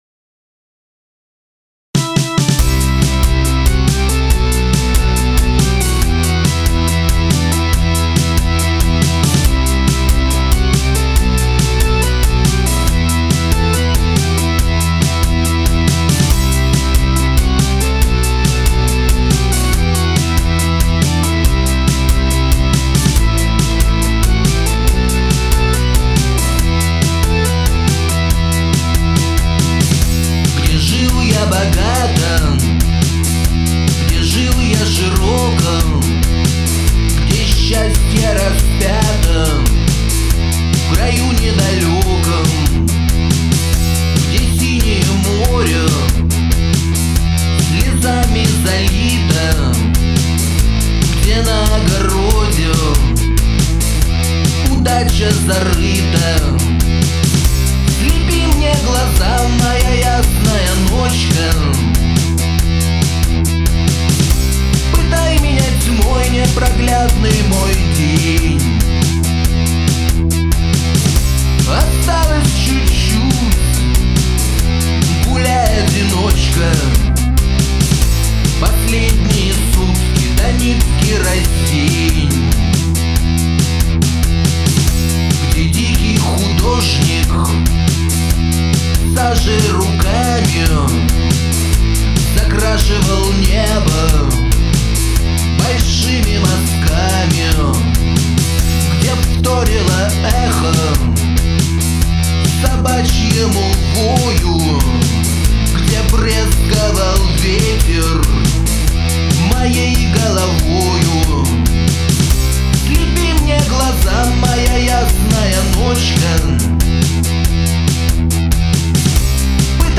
Стерео stereo